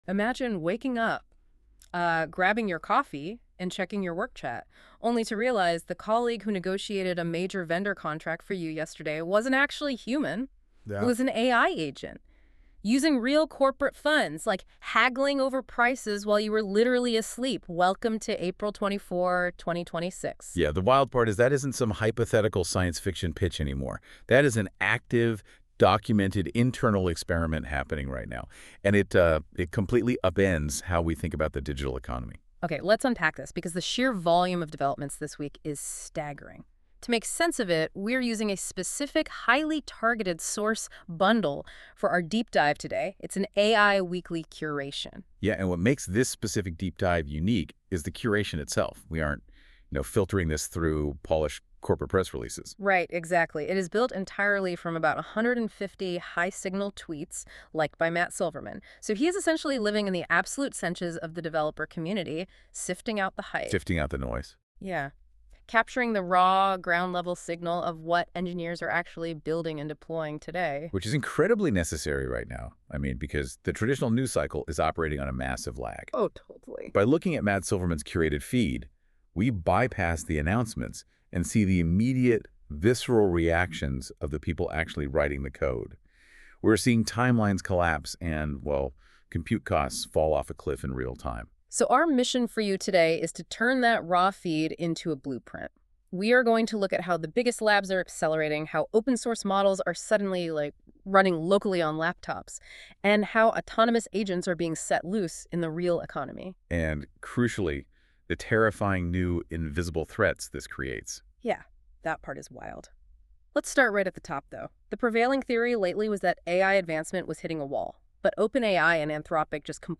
NotebookLM audio overview